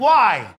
На этой странице собраны аудиозаписи с голосом Дональда Трампа: знаменитые высказывания, фрагменты выступлений и публичных речей.